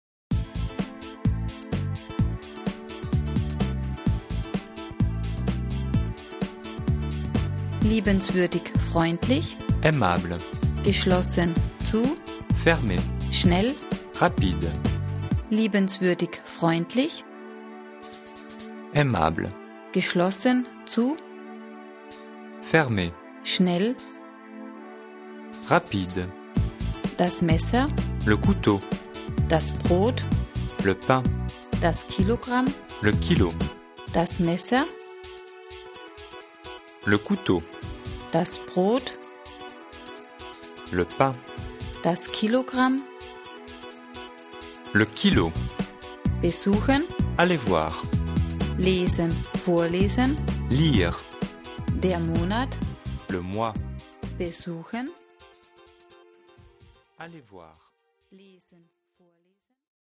Und Sie haben immer den perfekten Native-Speaker zur Kontrolle und Verbesserung Ihrer Aussprache dabei.
Musik ist ein wichtiger Bestandteil des Lernsystems; sie ermöglicht ein relaxtes, schnelles Lernen und macht auch noch so richtig Spaß.
Sie hören zuerst das deutsche Vokabel und danach die französische Übersetzung. Nach drei Vokabeln wird der soeben gelernte Block mit einer Sprechpause, in der Sie die Übersetzung laut aussprechen, abgeprüft.